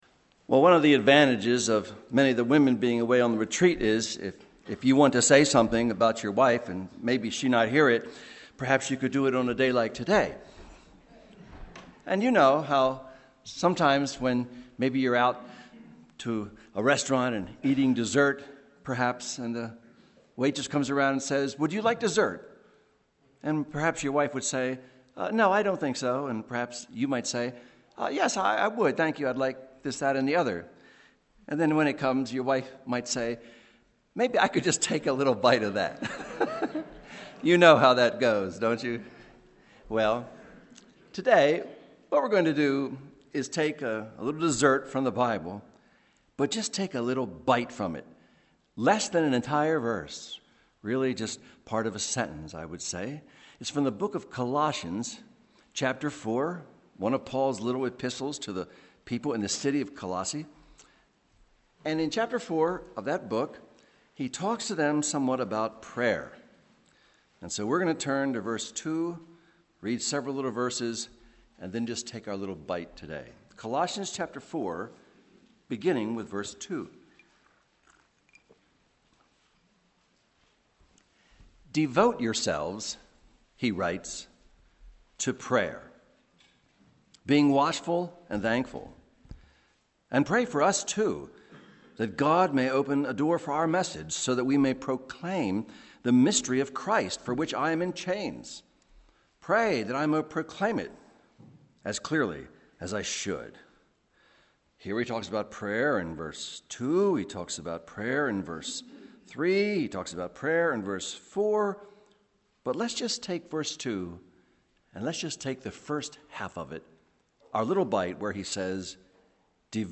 MP3 audio sermons from Brick Lane Community Church in Elverson, Pennsylvania.